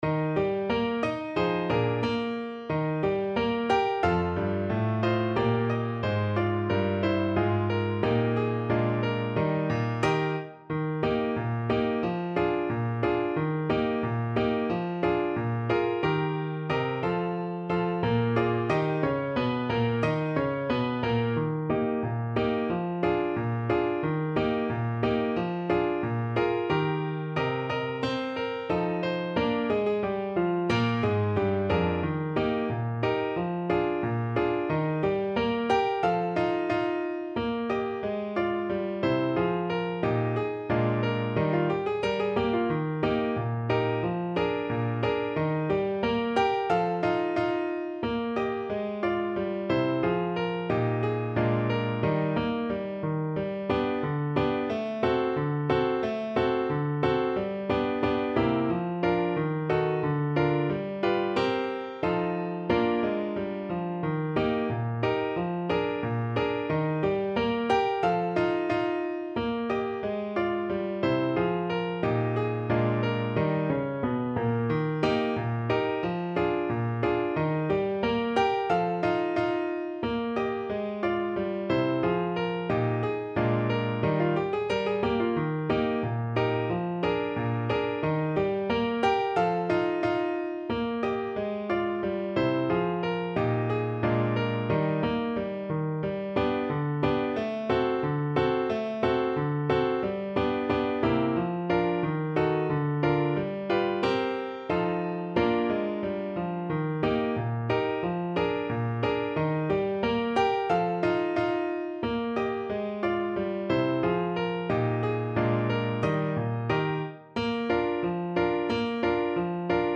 2/2 (View more 2/2 Music)
=90 Fast and cheerful